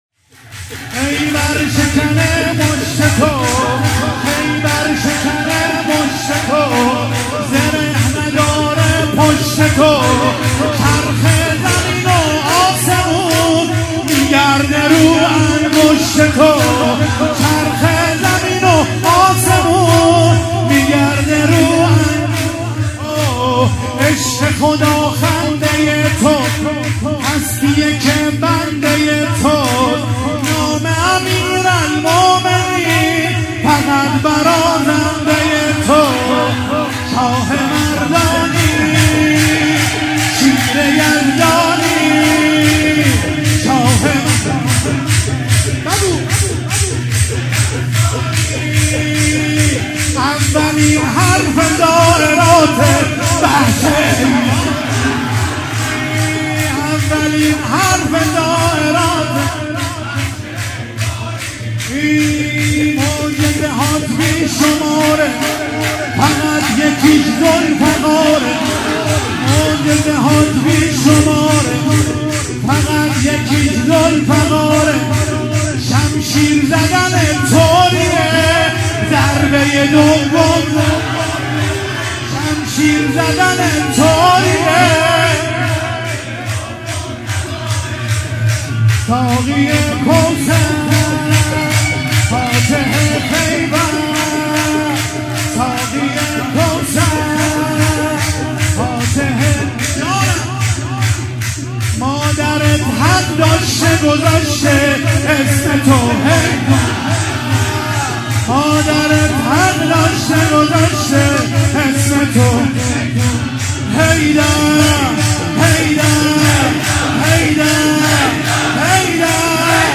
مولودی- خیبر شکنه مشت تو